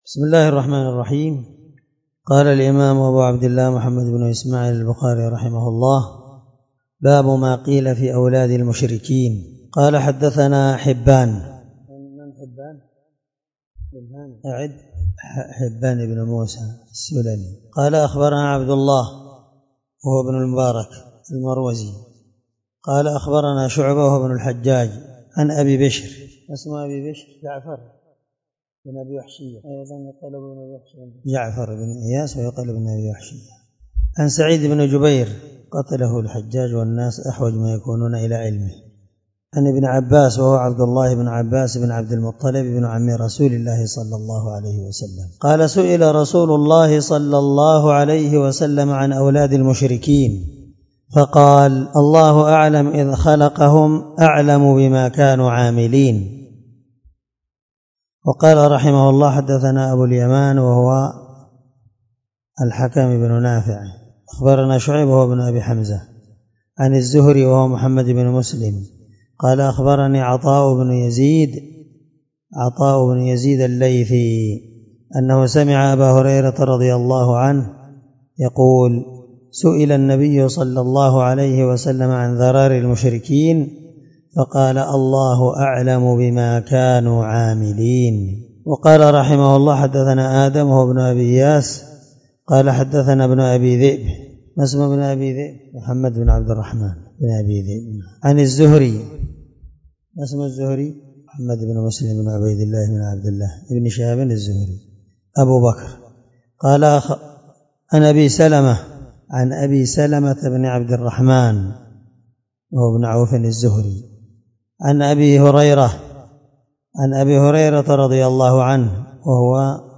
795الدرس 68من شرح كتاب الجنائز حديث رقم(1382-1385 )من صحيح البخاري